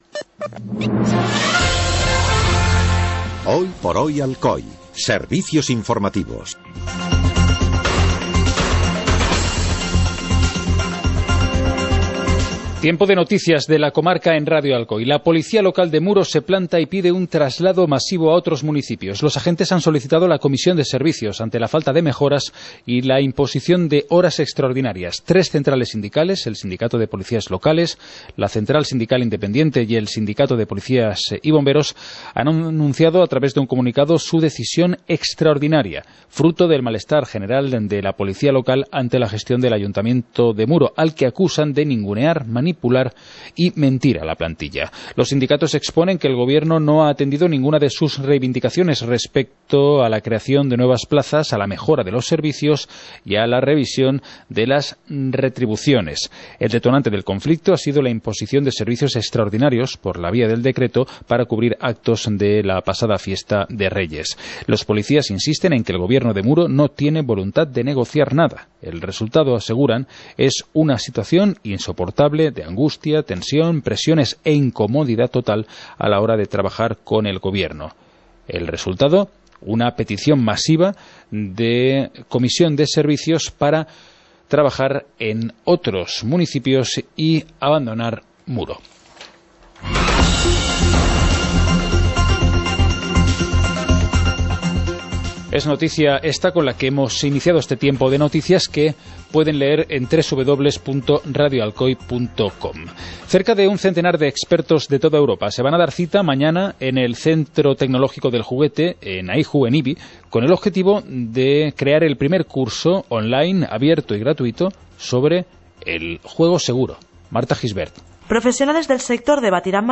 Informativo comarcal - miércoles, 14 de febrero de 2018